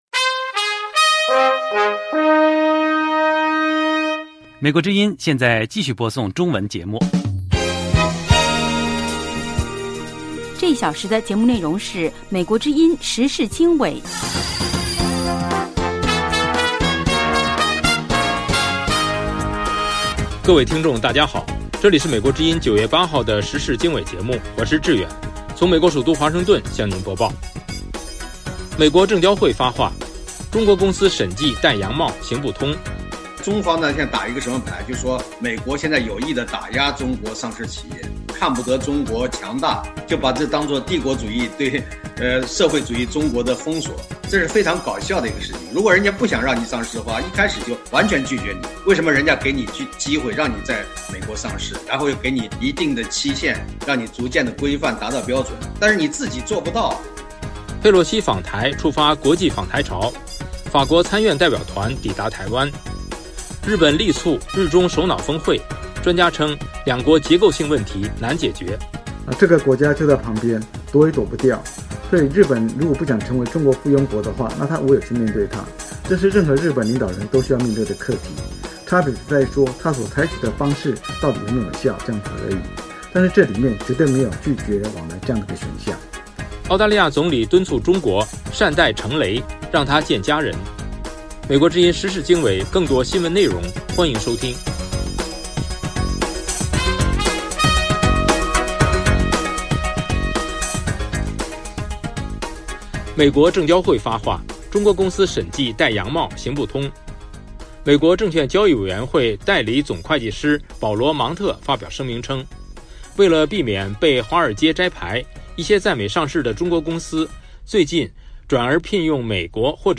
时事经纬(2022年9月8日)：1/连线采访：美国证交会发话，中国公司审计“戴洋帽”行不通。